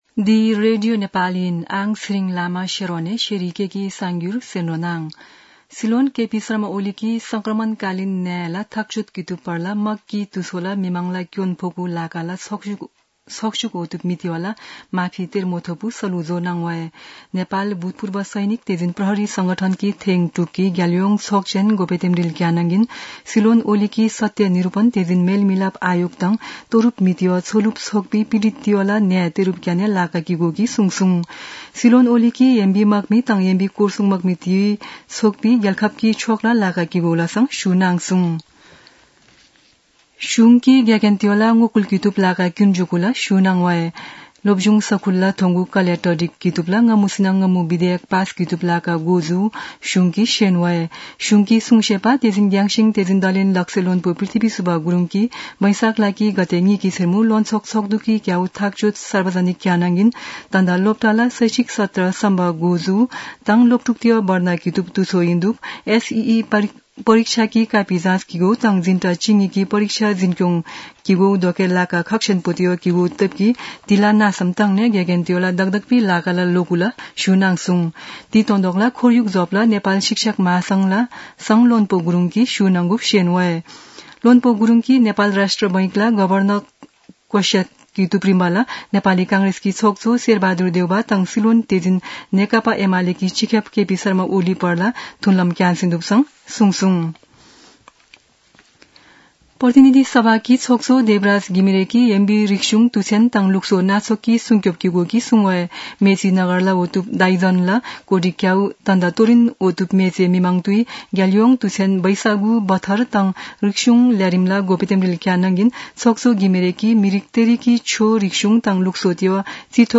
शेर्पा भाषाको समाचार : ४ वैशाख , २०८२